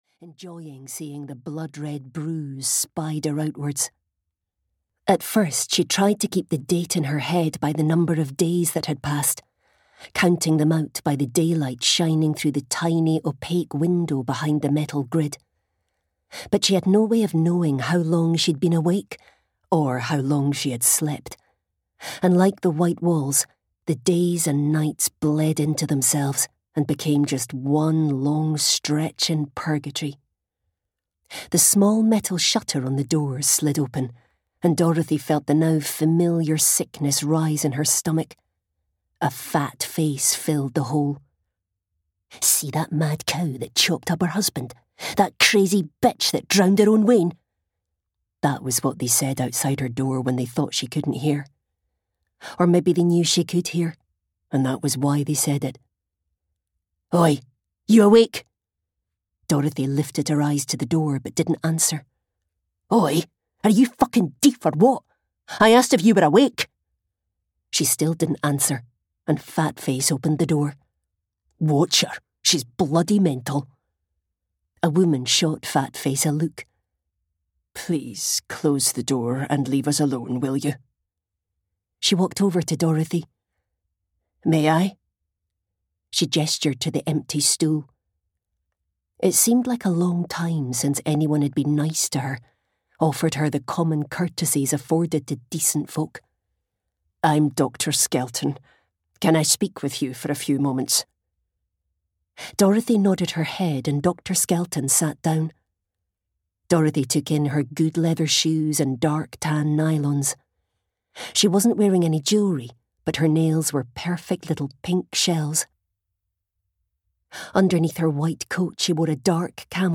Audiobook Keep Her Silent, written by Theresa Talbot.
Ukázka z knihy